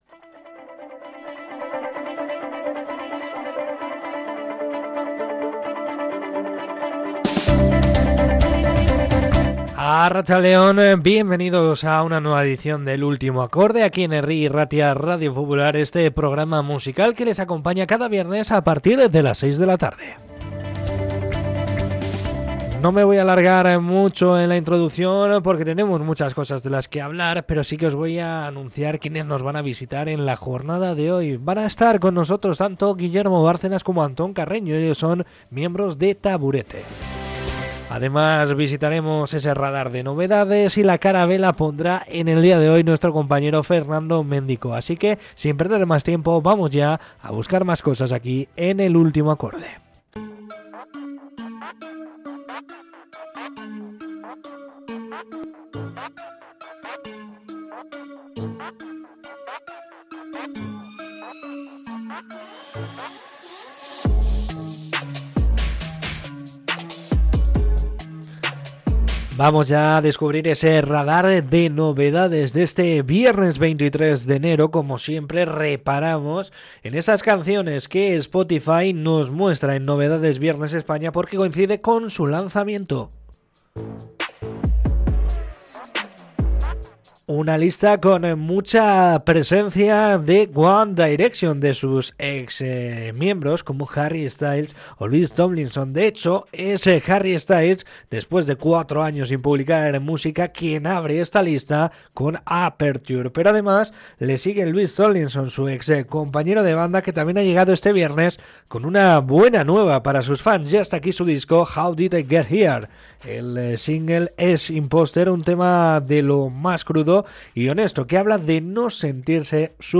Charla con Taburete, recordamos el primer Salón de la Fama del Rock y las mejores novedades